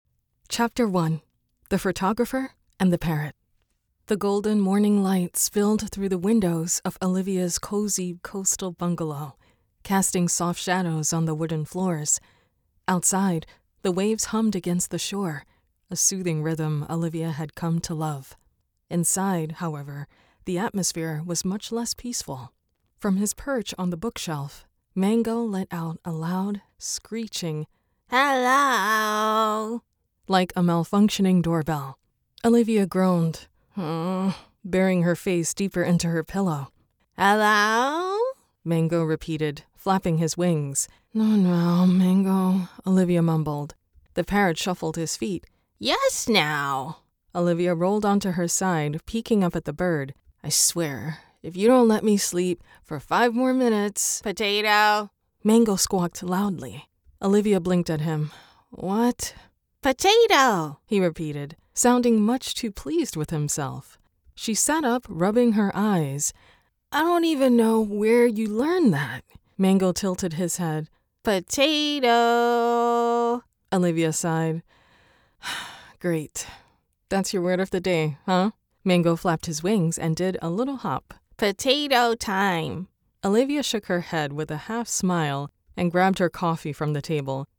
Femelle
Livres audio
MKH 416, Scarlet Solo, MacBook Air
Mezzo-soprano